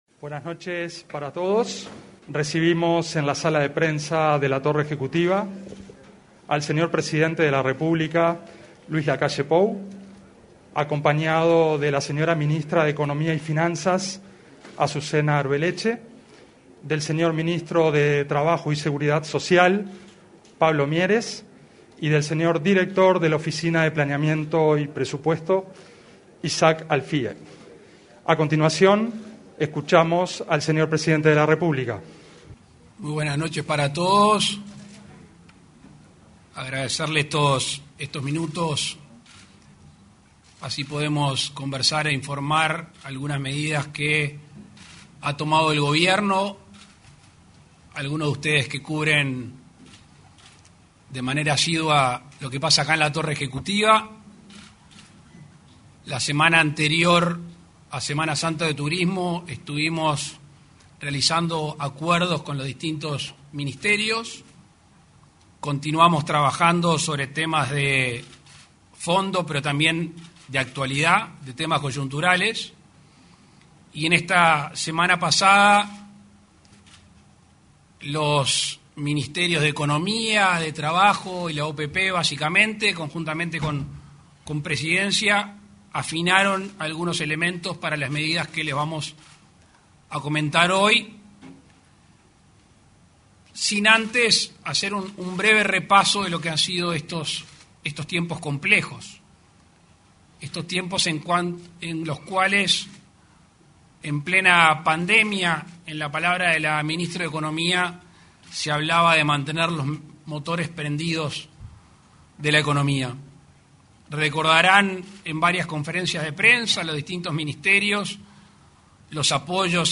Conferencia de prensa del presidente de la República, Luis Lacalle Pou
Conferencia de prensa del presidente de la República, Luis Lacalle Pou 18/04/2022 Compartir Facebook X Copiar enlace WhatsApp LinkedIn Este lunes 18, el presidente de la República, Luis Lacalle Pou, acompañado por la ministra de Economía y Finanzas, Azucena Arbeleche; el ministro de Trabajo y Seguridad Social, Pablo Mieres, y el director de la Oficina de Planeamiento y Presupuesto, Isaac Alfie, se expresó en conferencia de prensa en la Torre Ejecutiva. Your browser does not allow play this audio field.. 19 minutos 20 segundos - 6.800 KB Descargar Archivo Enlaces relacionados Fotos El presidente Luis Lacalle Pou anunció medidas económicas en conferencia de prensa.